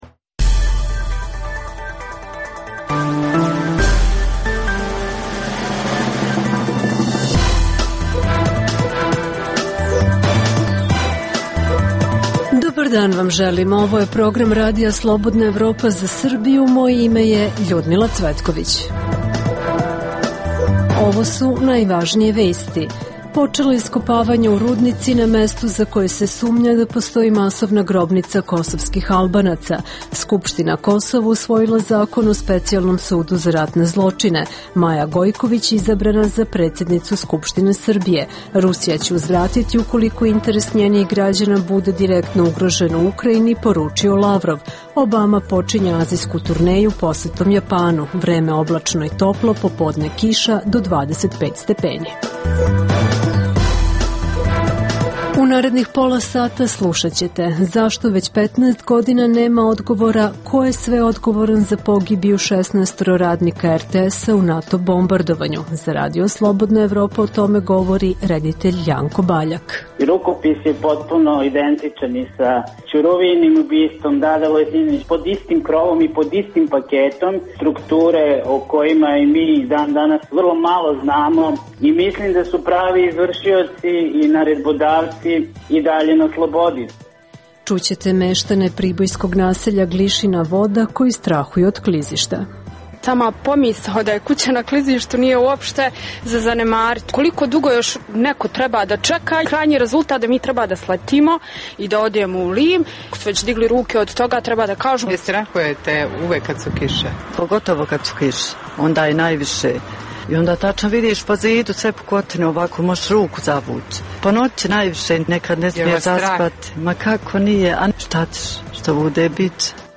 U emisiji poslušajte: - Počela iskopavanja u Rudnici na mestu za koje se sumnja da postoji masovna grobnica kosovskih Albanaca. Sa lica mesta izveštavaju naši reporteri.